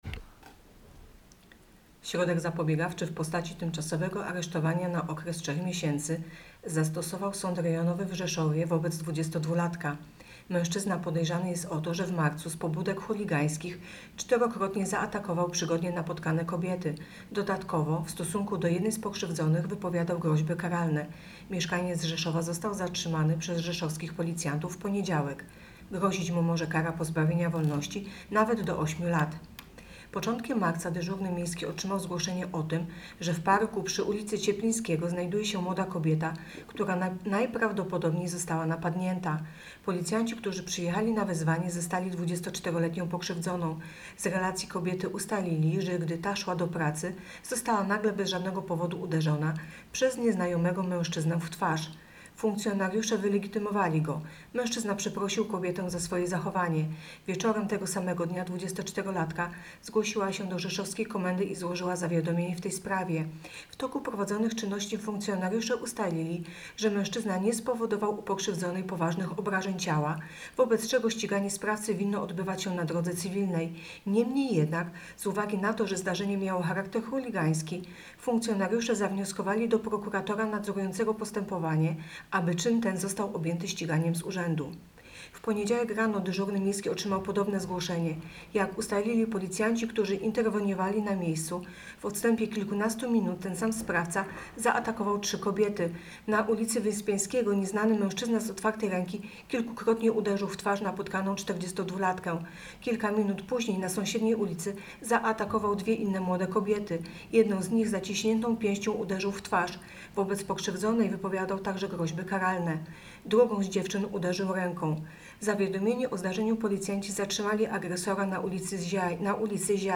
Opis nagrania: Nagranie informacji pt. Areszt dla 22-latka, który napadał na kobiety.